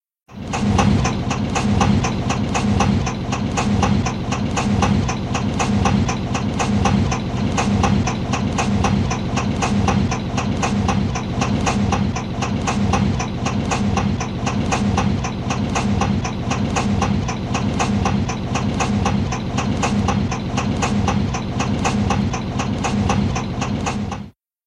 Звуки сломанной машины
Двигатель вот-вот стуканет